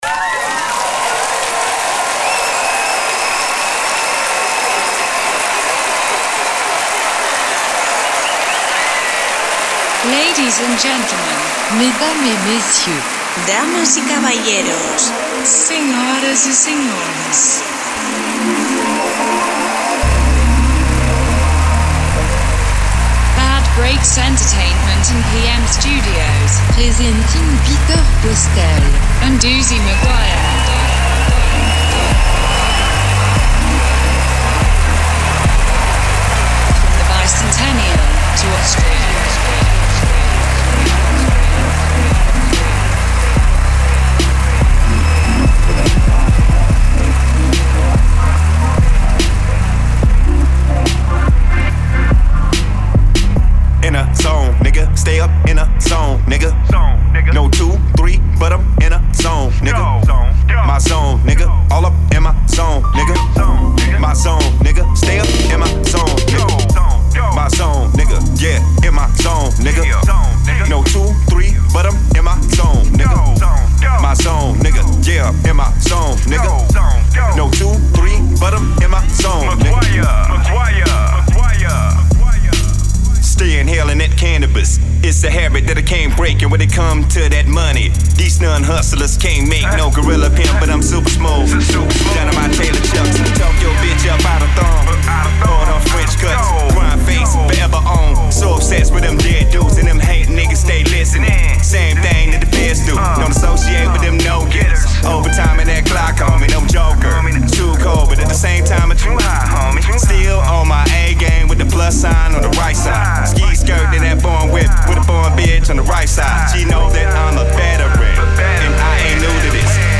Rap-HipHop